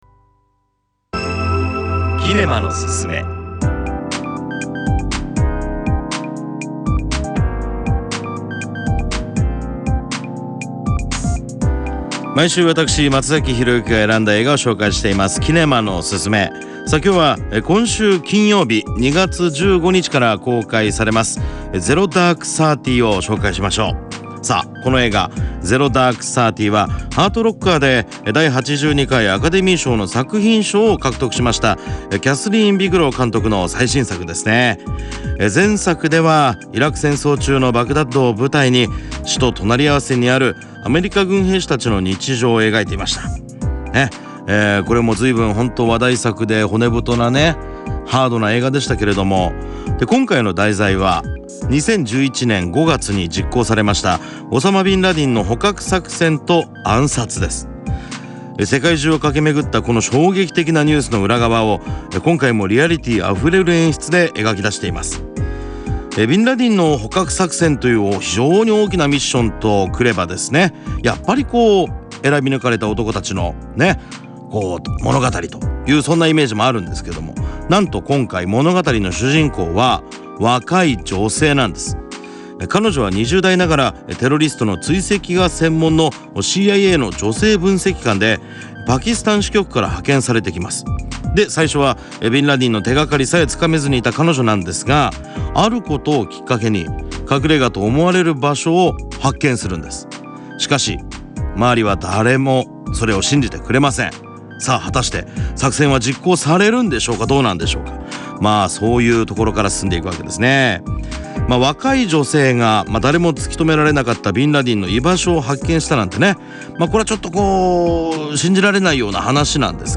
FMラジオ局・エフエム熊本のポッドキャスト。
2013年2月12日（火）オンエアの「キネマのススメ」(映画『ゼロ・ダーク・サーティ』）のコーナーをポッドキャストします。